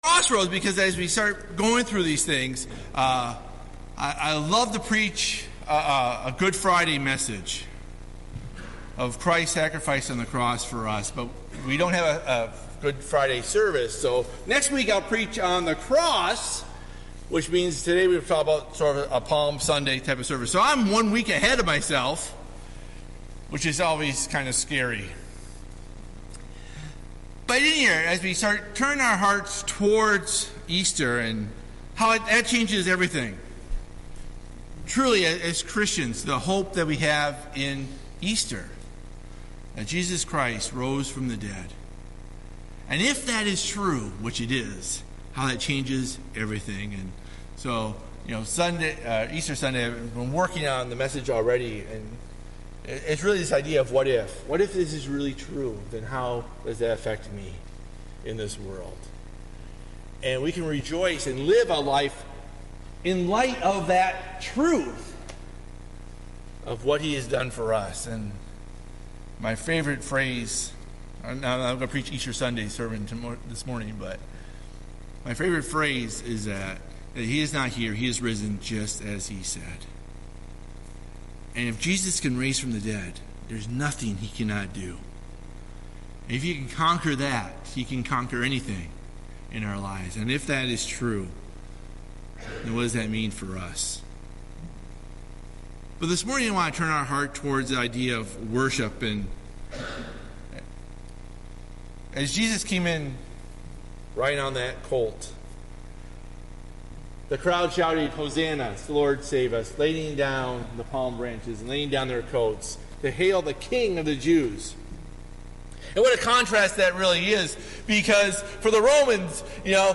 Sunday-Worship-Should-the-Stones-Cry-Out.mp3